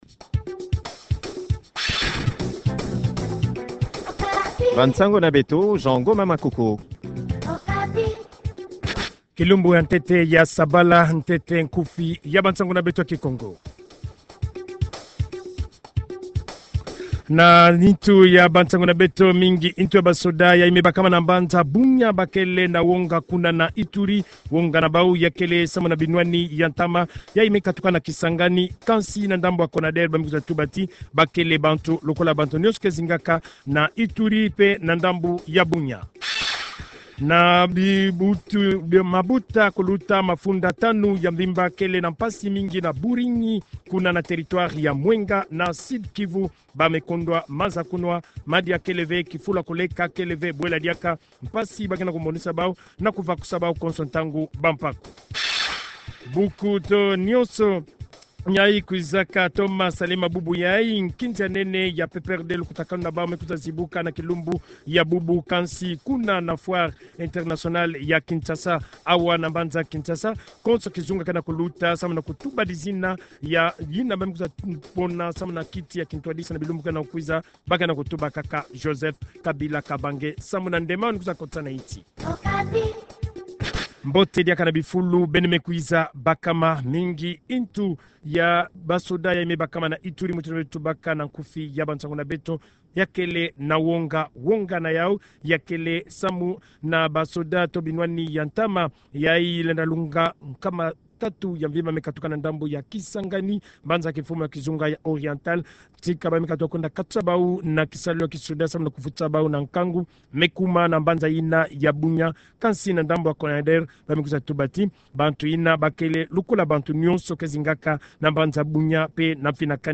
Journal Kikongo